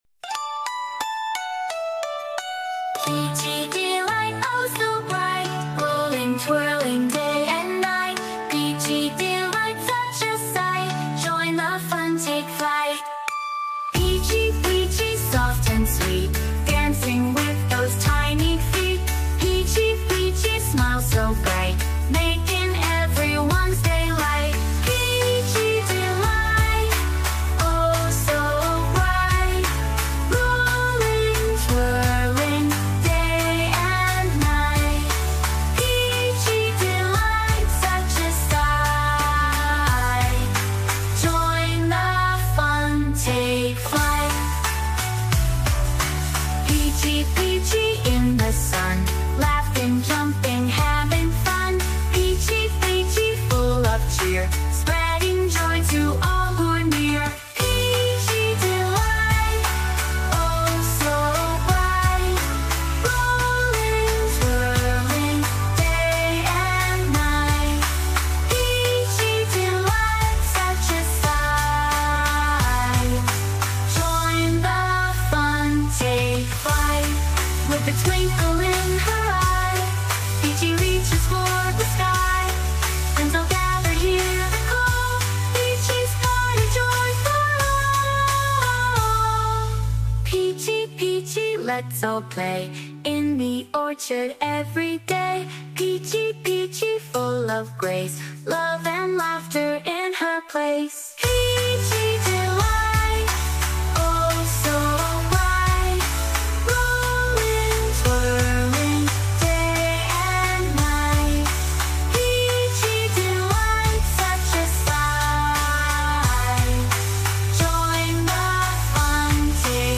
Nursery Rhymes & Kids Songs